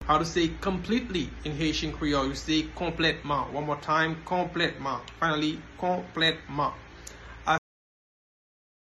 Pronunciation:
Completely-in-Haitian-Creole-Konpletman-pronunciation-by-a-Haitian-teacher.mp3